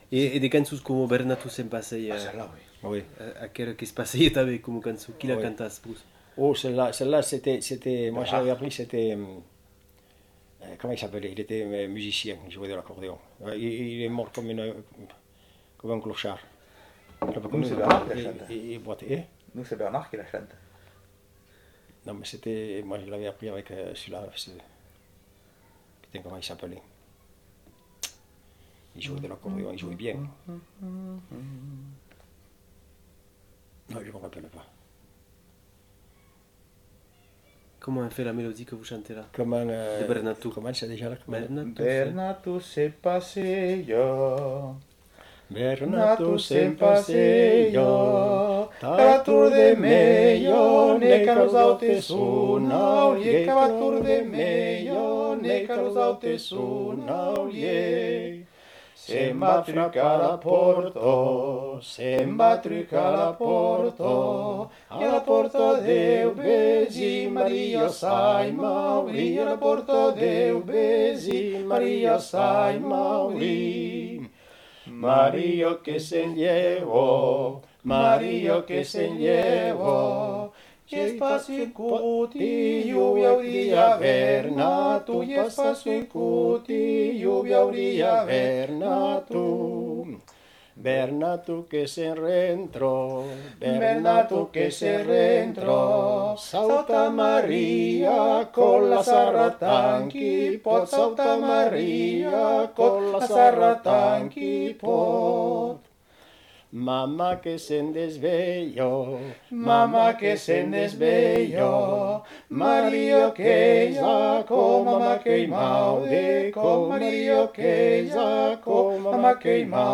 Aire culturelle : Bigorre
Lieu : Villelongue
Genre : chant
Effectif : 2
Type de voix : voix d'homme
Production du son : chanté
Descripteurs : polyphonie